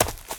High Quality Footsteps
STEPS Leaves, Run 12.wav